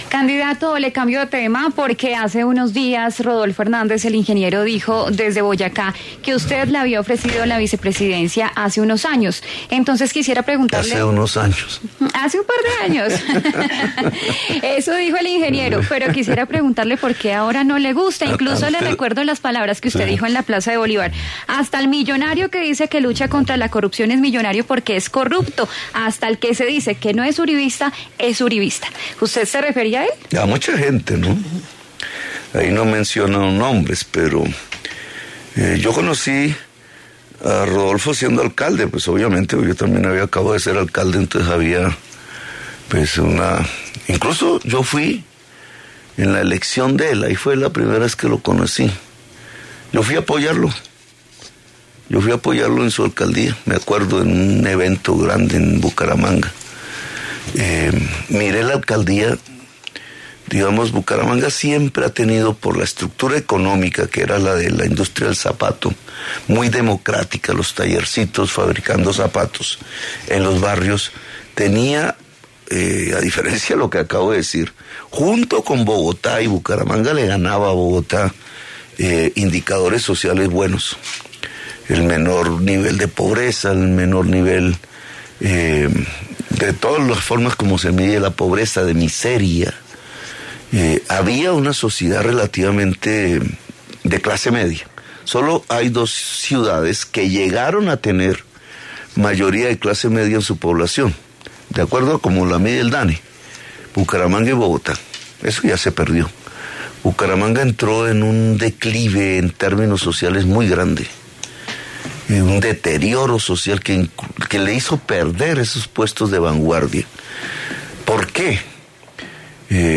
En diálogo con La W, el candidato presidencial Federico ‘Fico’ Gutiérrez mencionó algunos nombres que podría llegar a considerar en un eventual gobierno suyo.